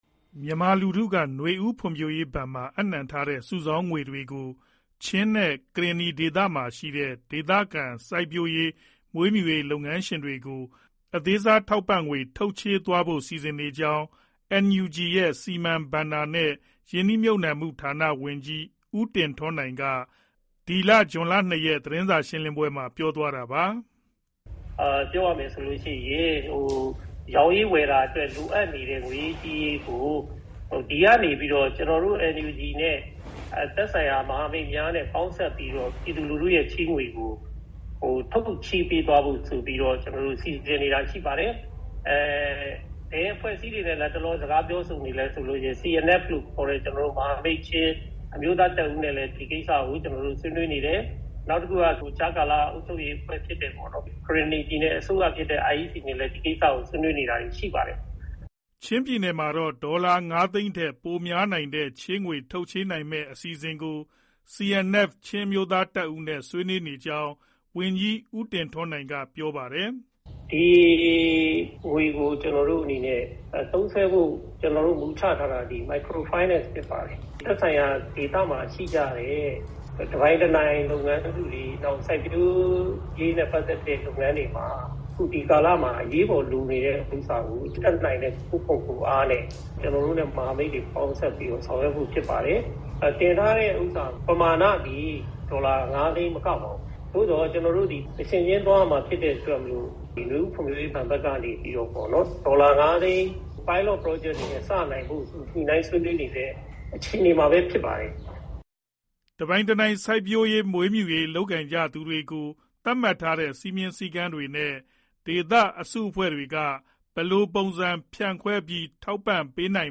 မြန်မာလူထုက နွေဦးဖွံ့ဖြိုးရေးဘဏ်မှာ အပ်နှံထားတဲ့ စုဆောင်းငွေတွေကို ချင်းနဲ့ ကရင်နီဒေသမှာရှိတဲ့ ဒေသခံ စိုက်ပျိုး၊ မွေးမြူရေး လုပ်ငန်းရှင်တွေကို အသေးစားထောက်ပံ့ငွေ ထုတ်ချေးပေးသွားဖို့ စီစဉ်နေကြောင်း NUG ရဲ့ စီမံ၊ ဘဏ္ဍာနဲ့ ရင်းနှီးမြှုပ်နှံ့မှုဌာနဝန်ကြီး ဦးတင်ထွန်းနိုင်က ဇွန်လ၂ရက် သတင်းစာရှင်းလင်းပွဲမှာ ပြောသွားတာပါ။